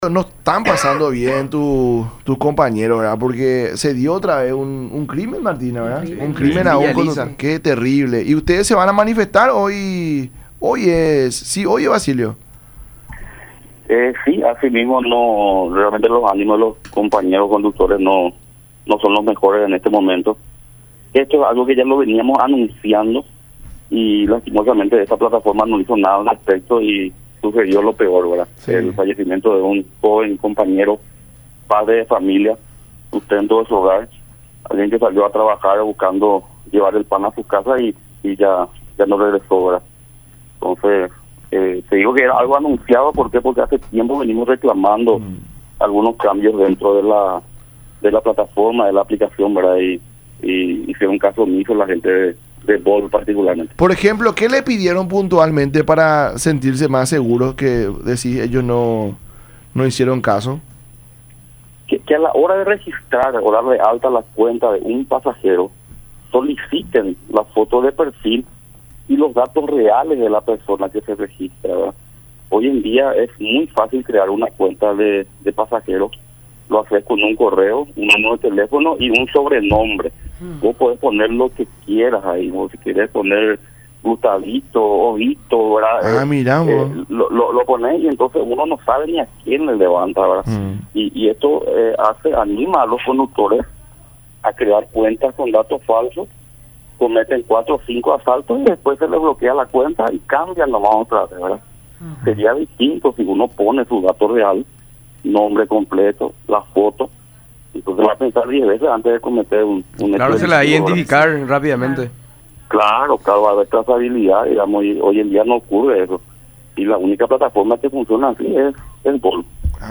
“Los niños que no tienen educación ni alternativa de vida, se convierten en pequeños delincuentes, está prohibido por ley que los niños en la calle trabajen y conmigo se va a respetar”, dijo en conversación con el programa “La Mañana De Unión” por Unión TV y radio La Unión.